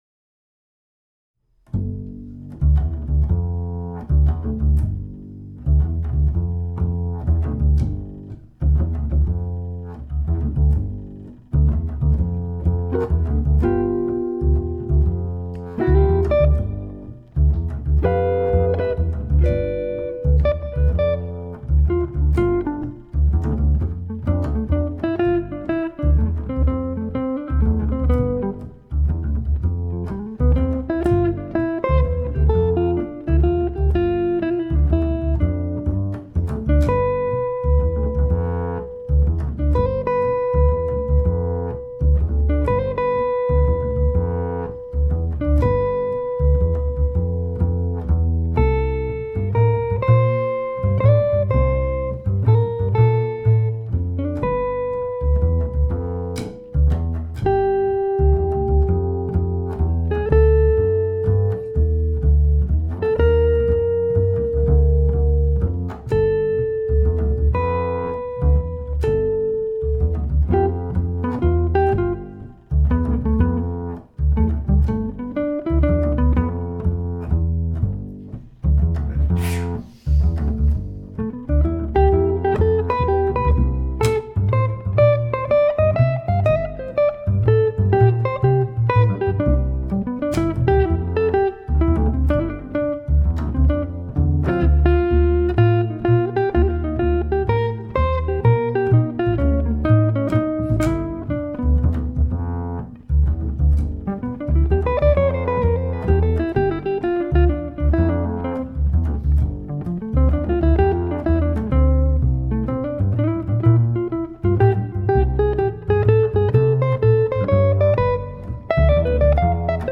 guitar og kontrabas duo
• Jazzband